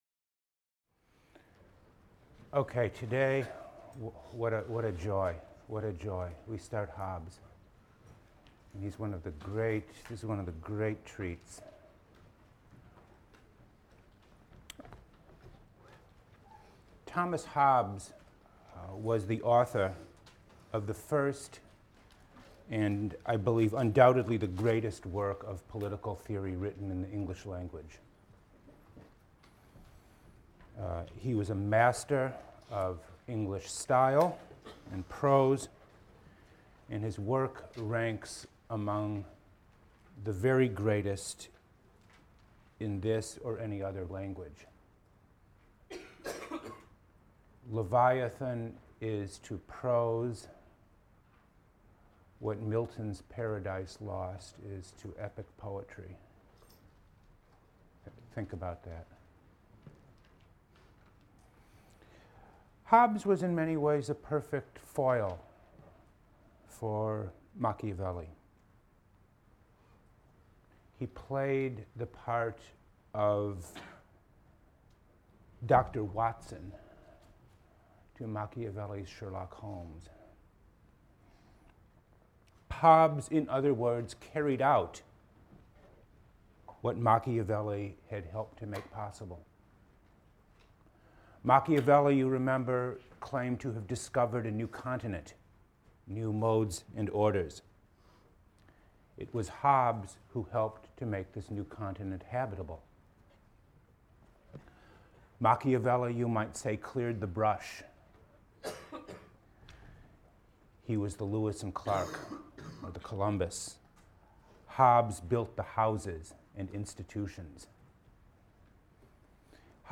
PLSC 114 - Lecture 12 - The Sovereign State: Hobbes, Leviathan | Open Yale Courses